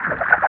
51 NOISE  -L.wav